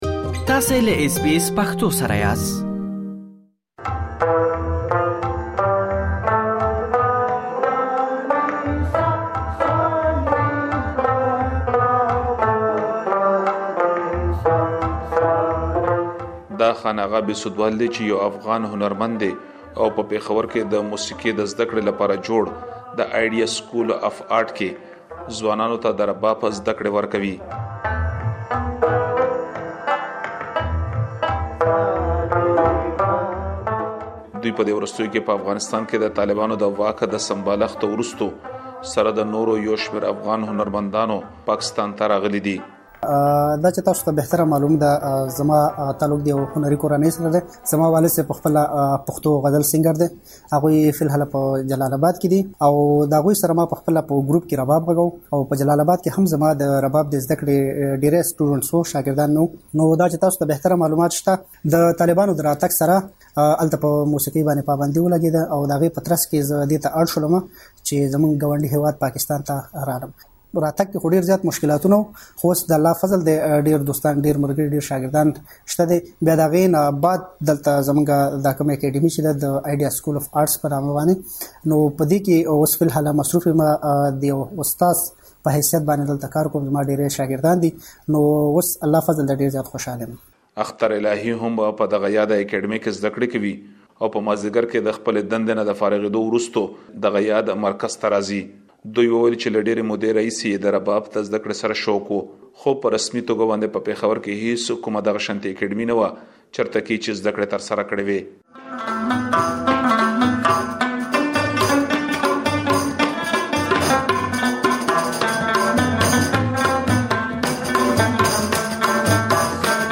رپوټ